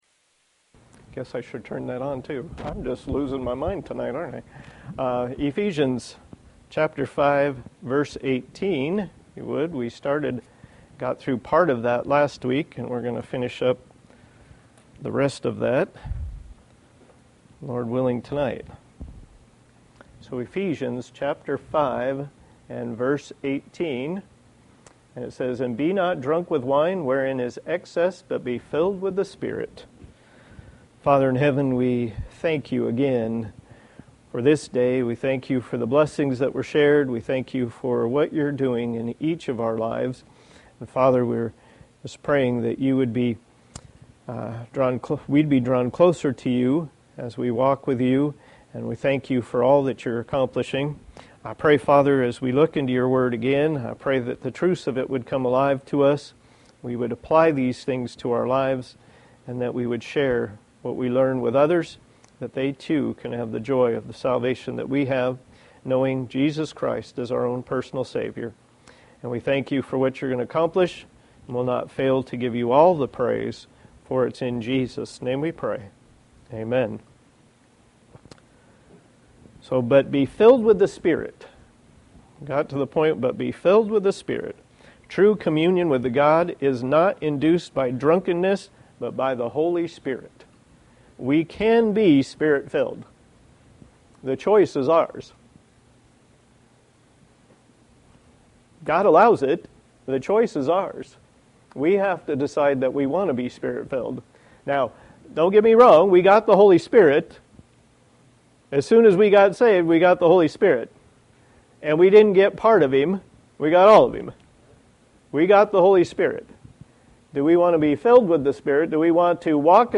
Passage: Ephesians 5:18 Service Type: Sunday Evening